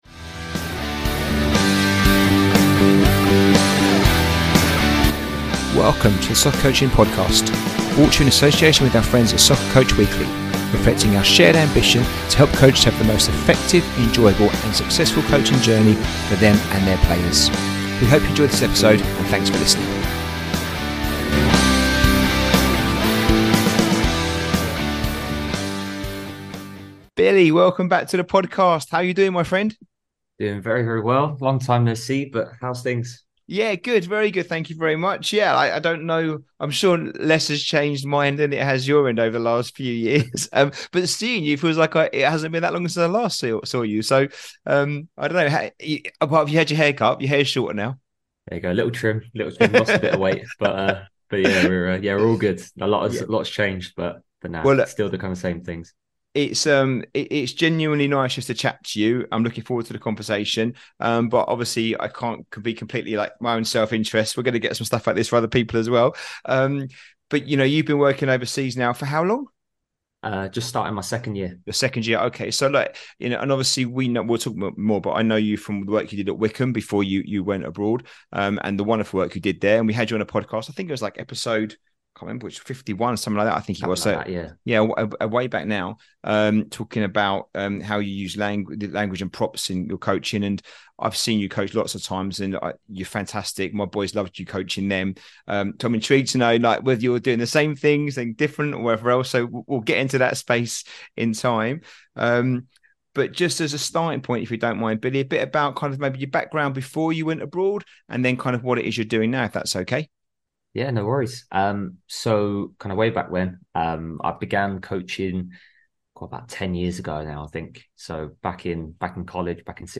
Episode 104 - How Coaching Abroad Has Helped Me Develop, a conversation